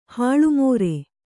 ♪ hāḷu mōre